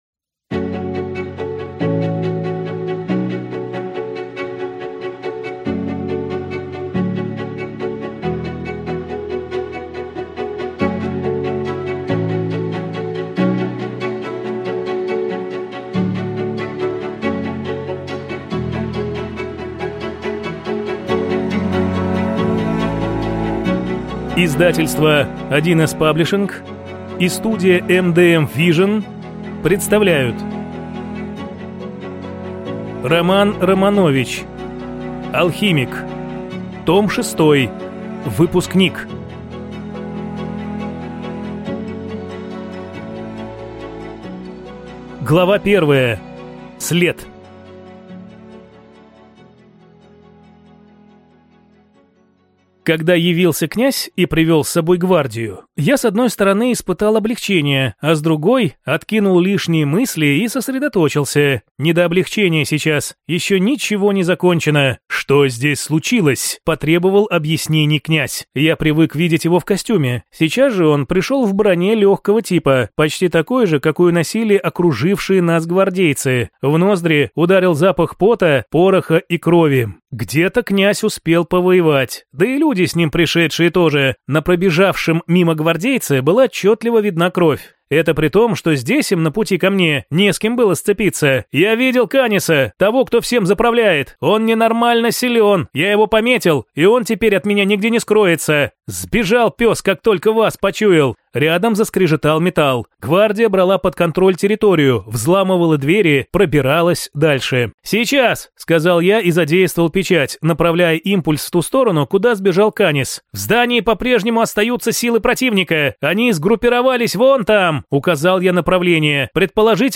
Алхимик. Том 6. Выпускник (слушать аудиокнигу бесплатно) - автор Роман Романович